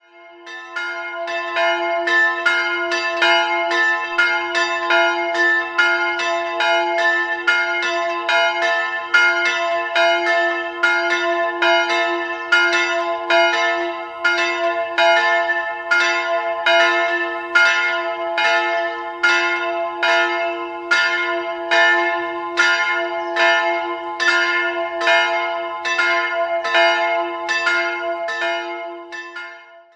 2-stimmiges Große-Terz-Geläute: f''-a'' Die größere Glocke (15. oder 16. Jh.) wurde von Nicolas Hirsperger gegossen, die kleinere Glocke enthält keinen Gießernamen und stammt noch aus dem 14. Jahrhundert.